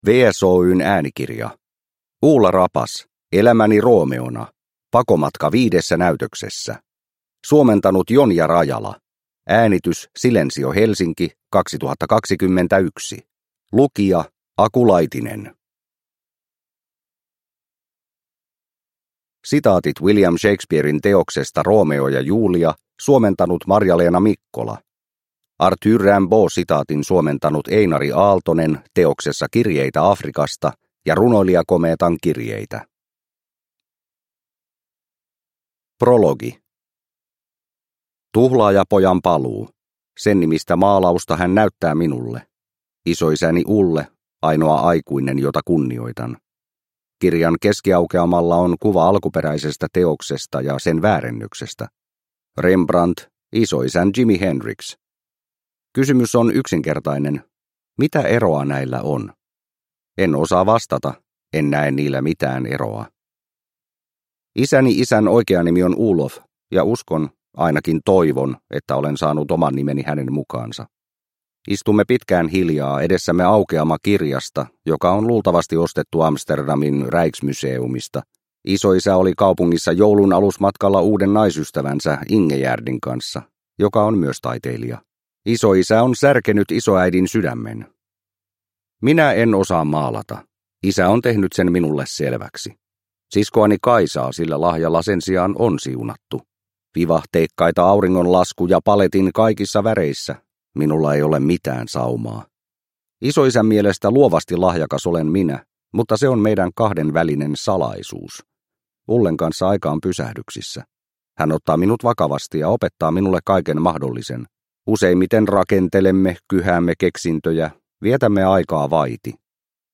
Elämäni Romeona – Ljudbok – Laddas ner